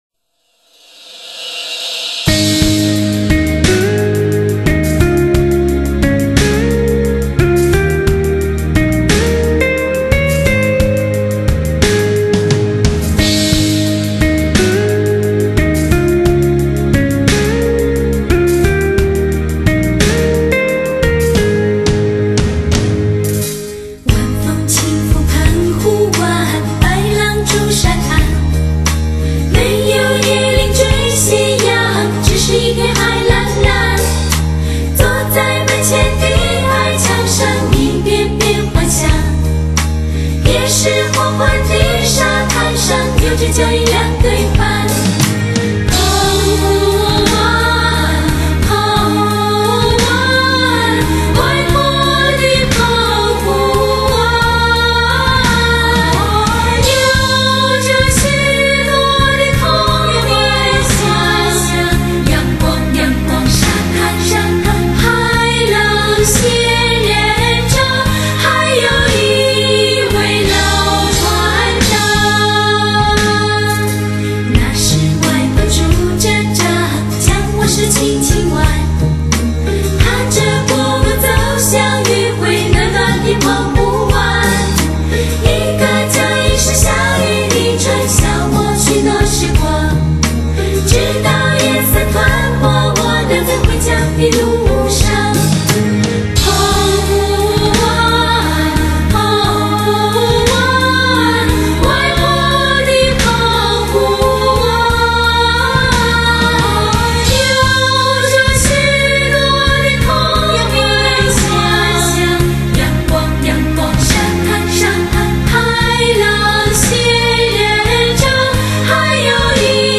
在最前衛的音樂伴奏下縯繹了15首深受人們喜愛的青春校園歌麯～!
低品質試聽麯目